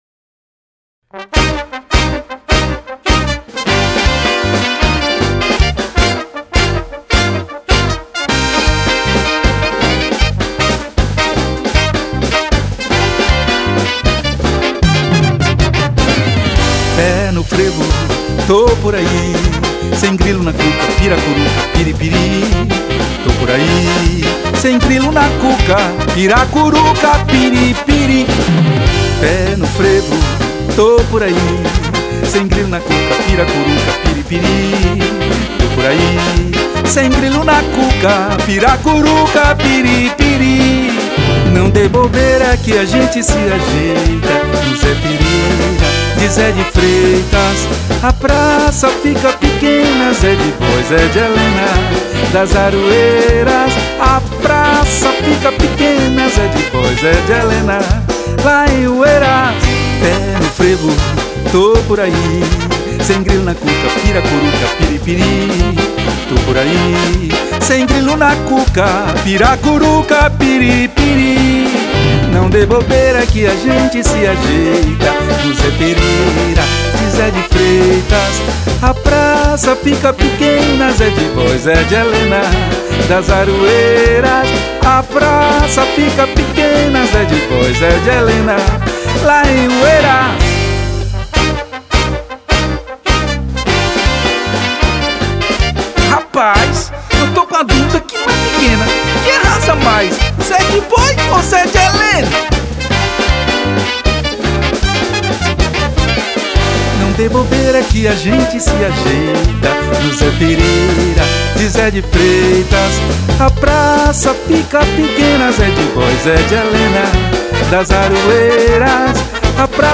374   05:21:00   Faixa:     Frevo